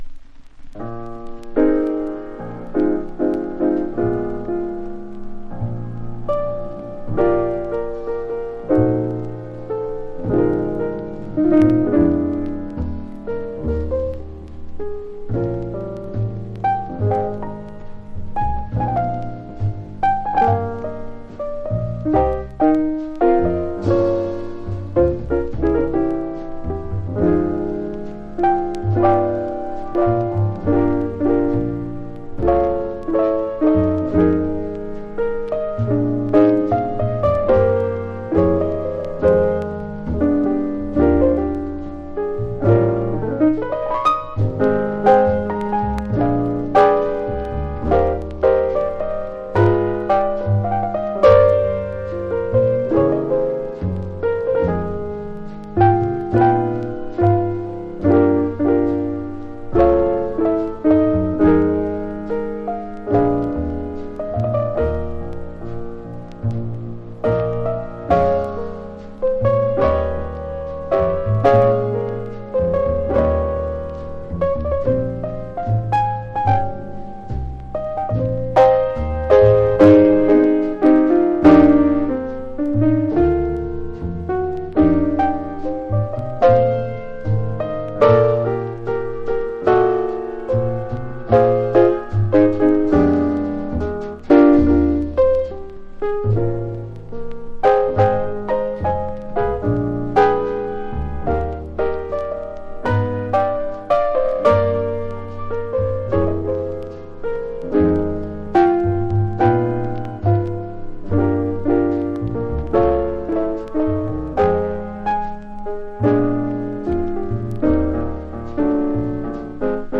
（プレス・小傷によりチリ、プチ音ある曲あり）
Genre PIANO TRIO